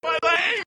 PLAY my leg sound effect
Play, download and share MY LEG original sound button!!!!